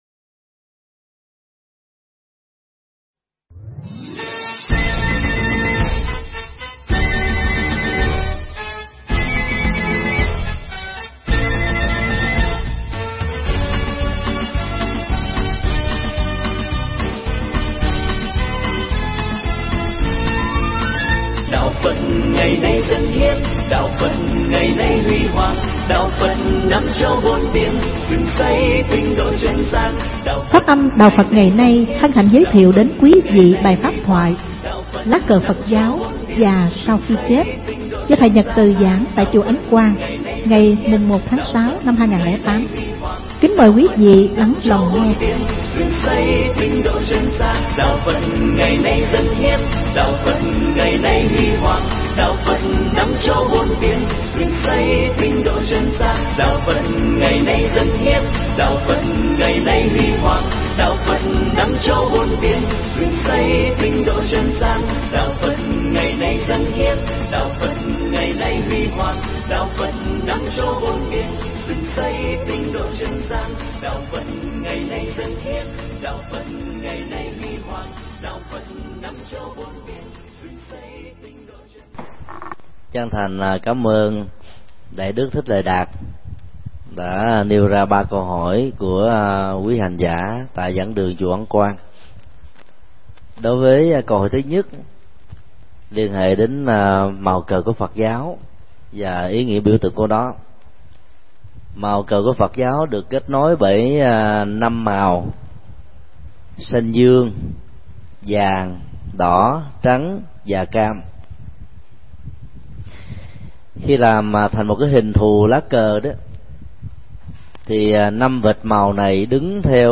Thuyết pháp
giảng tại Chùa Ấn Quang